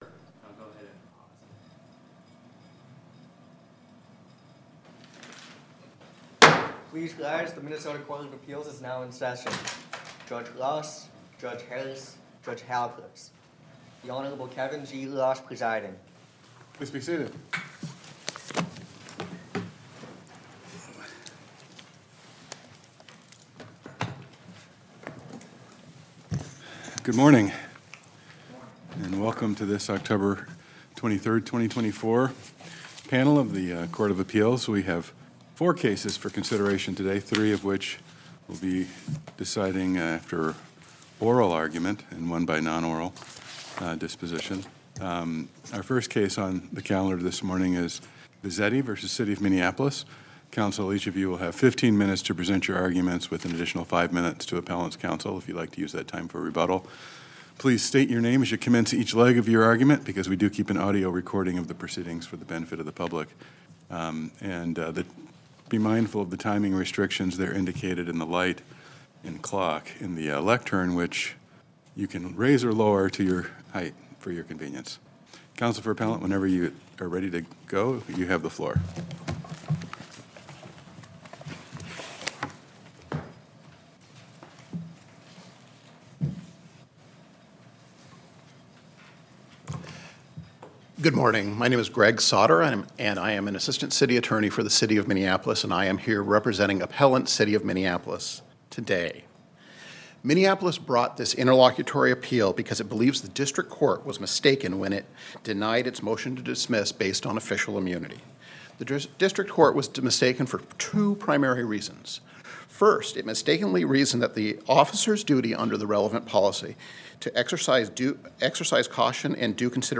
The Minnesota Court of Appeals provides the citizens of Minnesota with prompt and deliberate review of all final decisions of the trial courts, state agencies, and local governments.
Minnesota Court of Appeals Oral Argument Audio Recording